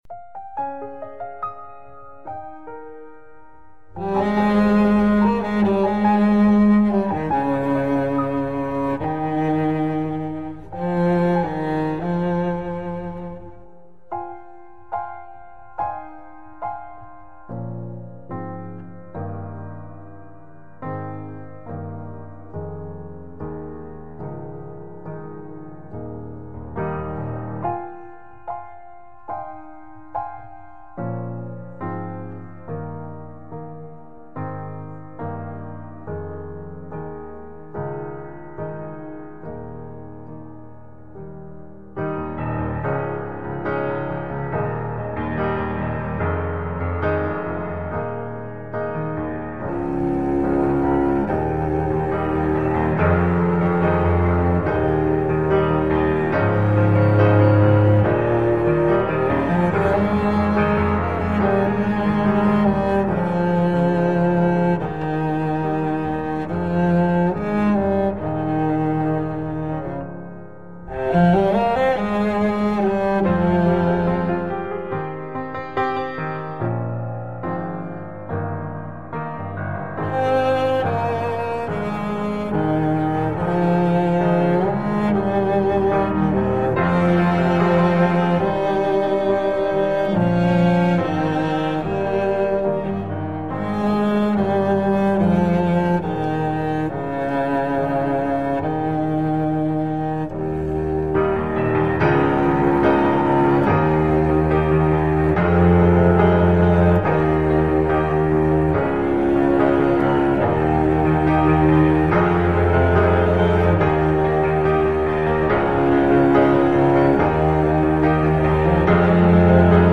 караоке
минусовка
теги: выпускной, песни переделки, пародия, минус